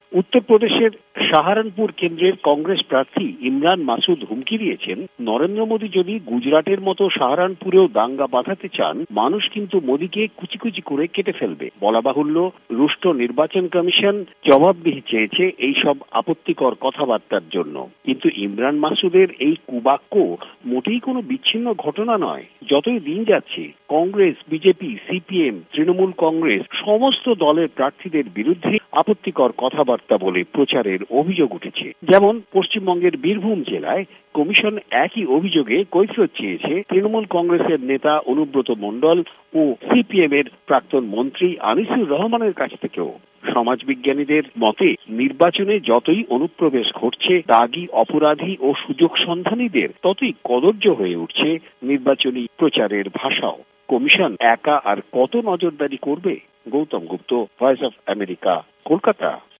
ভয়েস অব আমেরিকার কোলকাতা সংবাদদাতাদের রিপোর্ট